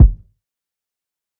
TC Kick 15.wav